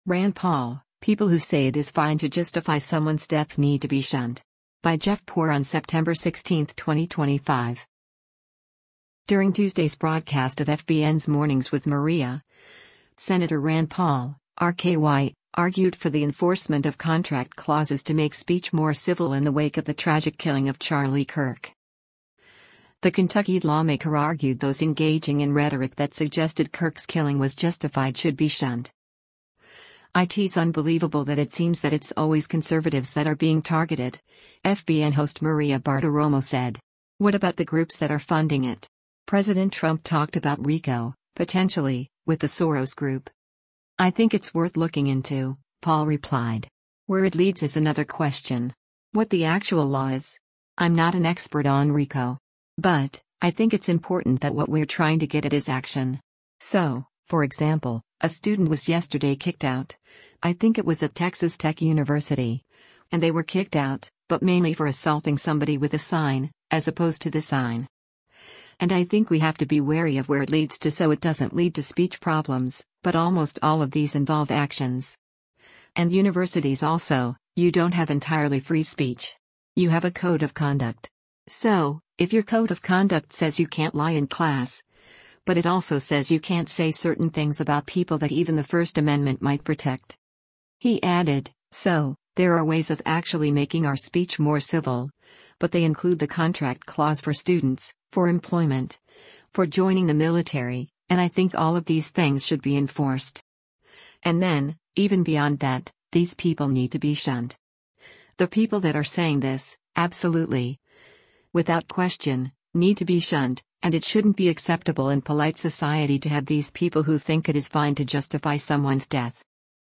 During Tuesday’s broadcast of FBN’s “Mornings with Maria,” Sen. Rand Paul (R-KY) argued for the enforcement of contract clauses to make speech more civil in the wake of the tragic killing of Charlie Kirk.